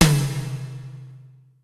Percusión 4: timbal 3
membranófono
timbal
percusión
batería
electrónico
golpe
sintetizador